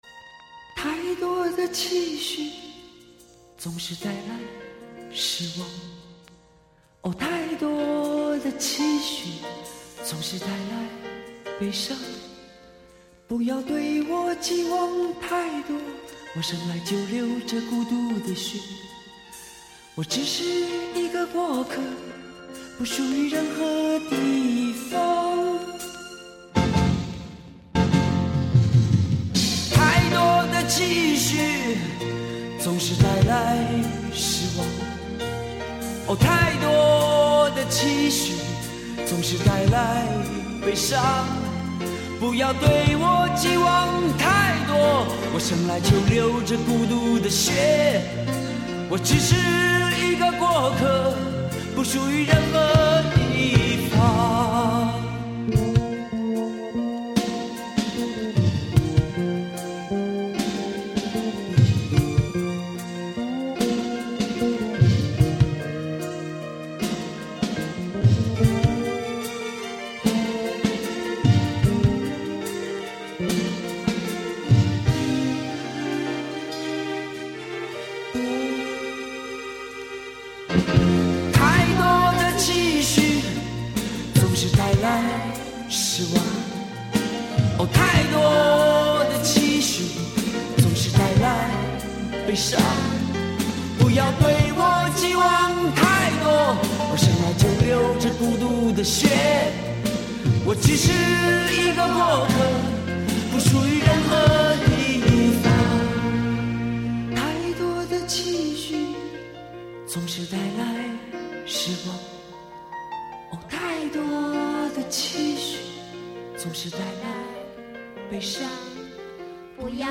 试听曲（低音质)